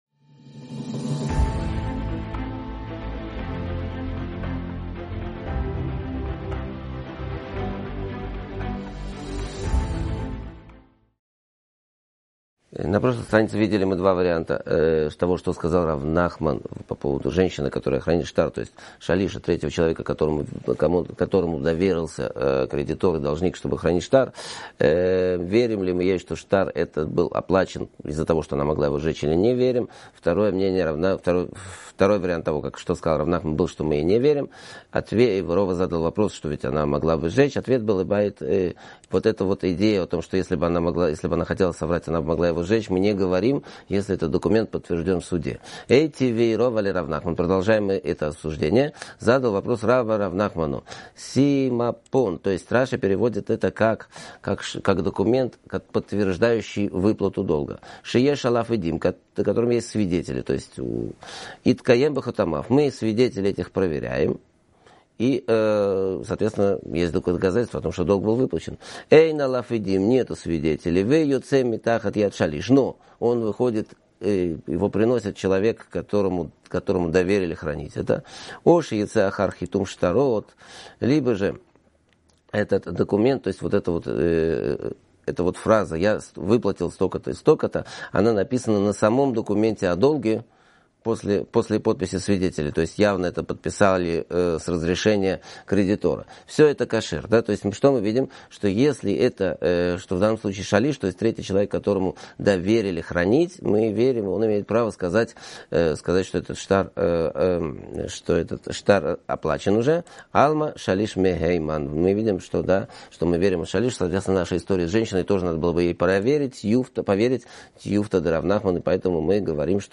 Урок